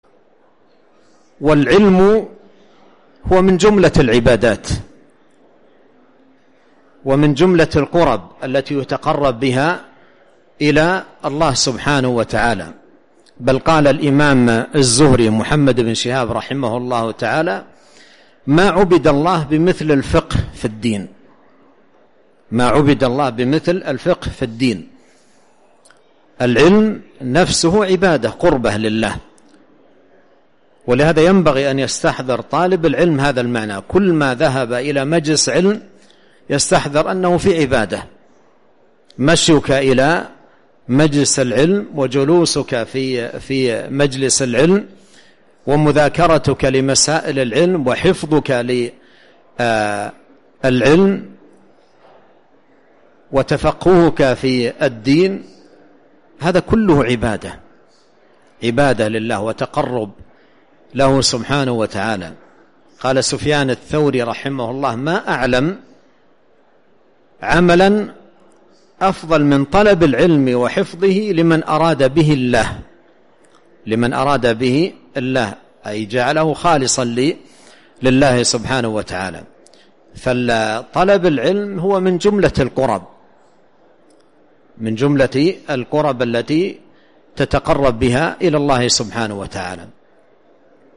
[من شرح آداب المعلمين والمتعلمين (01)]